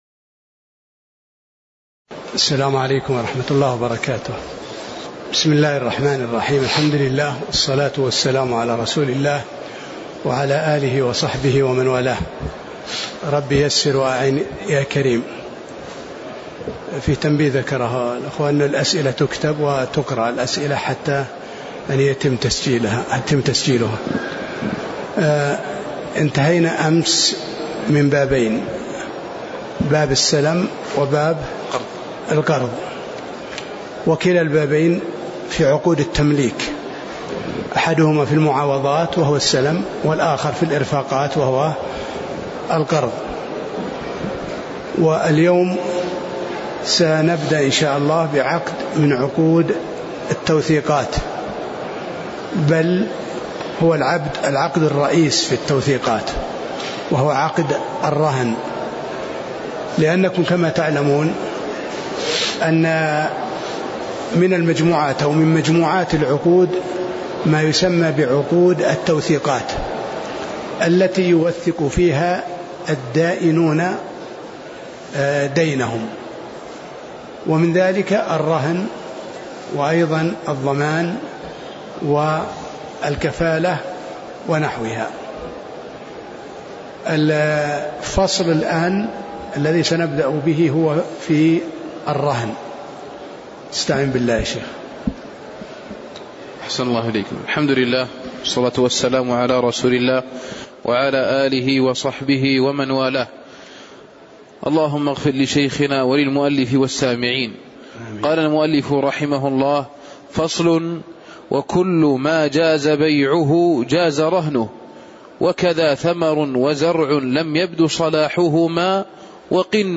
تاريخ النشر ٢ ربيع الثاني ١٤٣٨ هـ المكان: المسجد النبوي الشيخ